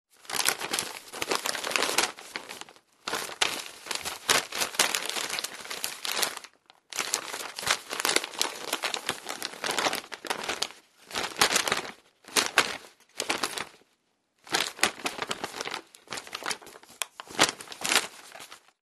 Хруст страниц журнала